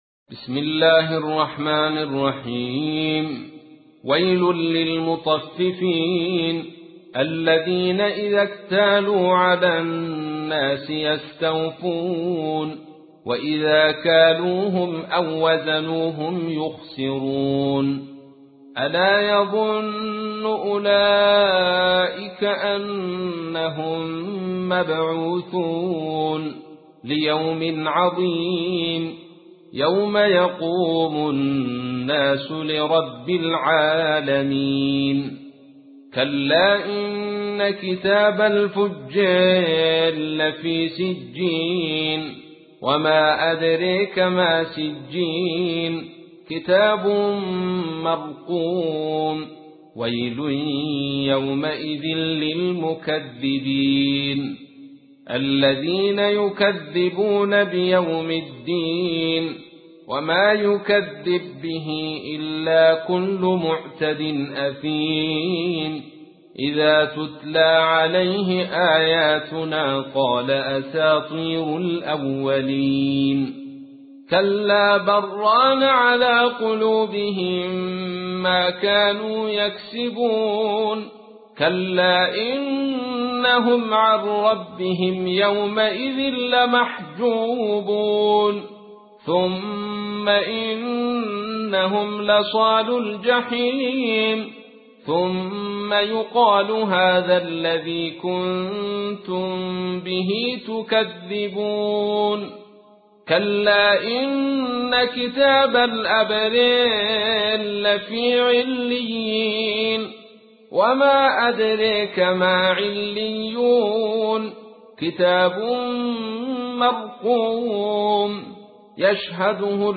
تحميل : 83. سورة المطففين / القارئ عبد الرشيد صوفي / القرآن الكريم / موقع يا حسين